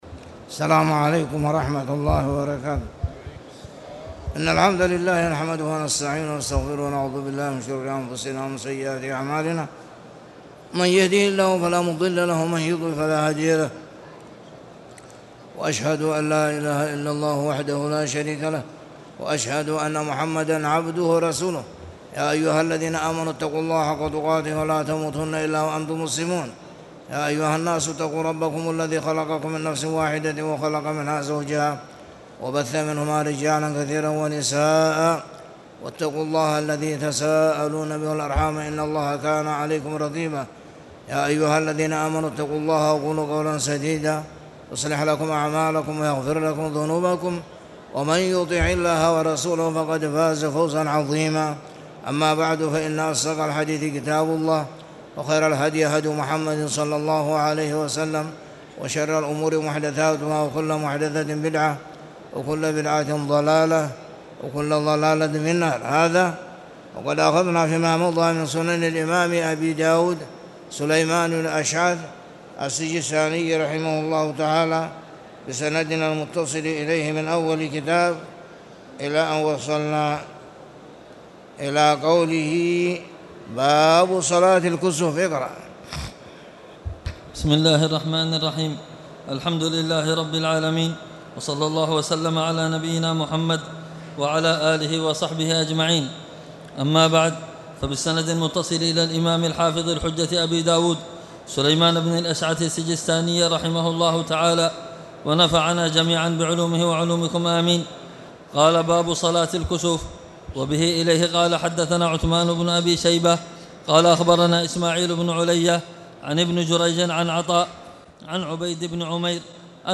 تاريخ النشر ٣ جمادى الأولى ١٤٣٨ هـ المكان: المسجد الحرام الشيخ